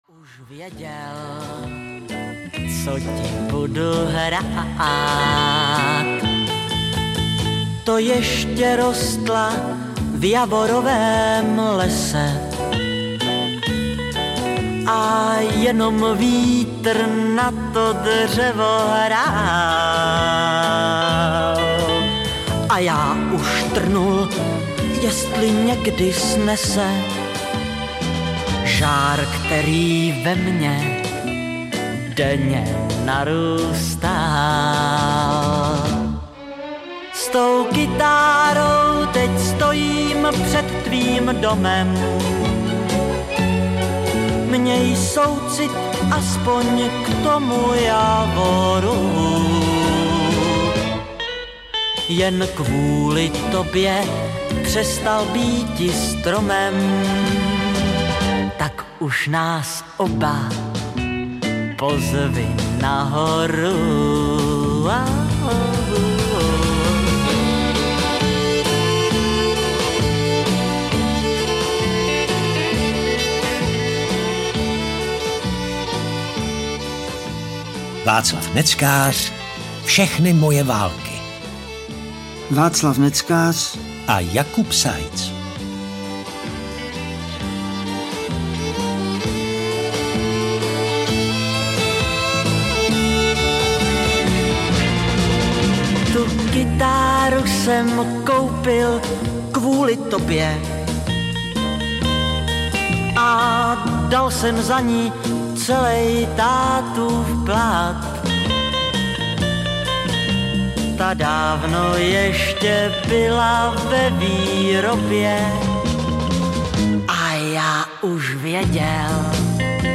Václav Neckář - Všechny moje války audiokniha
Ukázka z knihy
Audioknižní podoba hudebníkovy autobiografie seznamuje posluchače s uměleckou i životní poutí jednoho z nejvýznamnějších interpretů naší pop-music a přináší i množství nových a překvapivých informací. Součástí audioknihy je také 19 známých hitů, které vycházejí též samostatně na digitálním kompilačním albu Všechny moje války – Písničky ze stejnojmenné audioknihy a jsou rovněž k poslechu na všech streamovacích platformách.